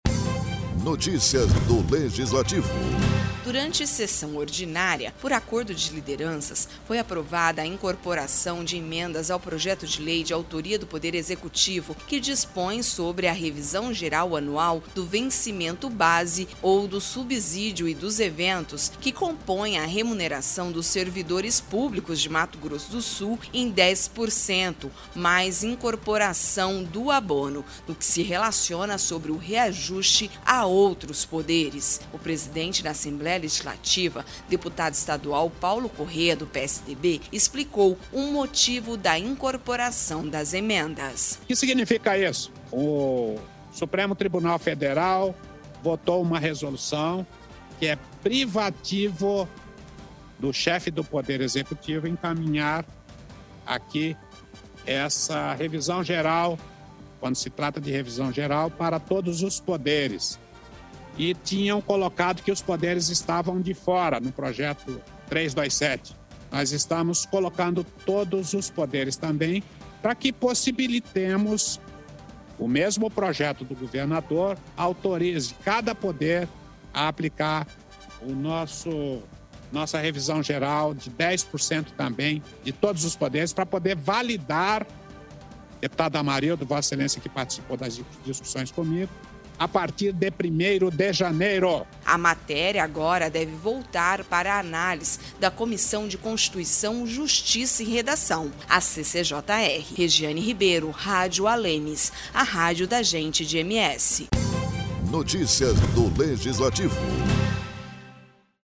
Durante sessão ordinária, por acordo de lideranças, foi aprovada a incorporação de emendas ao Projeto de Lei de autoria do Poder Executivo, que dispõe sobre a revisão geral anual do vencimento-base ou do subsídio e dos eventos, que compõem a remuneração dos servidores públicos de Mato Grosso do Sul em 10% mais incorporação do abono, no que se relaciona sobre o reajuste a outros Poderes.